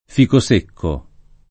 fico secco
vai all'elenco alfabetico delle voci ingrandisci il carattere 100% rimpicciolisci il carattere stampa invia tramite posta elettronica codividi su Facebook fico secco [ f & ko S% kko ] locuz. m. — anche ficosecco [ id.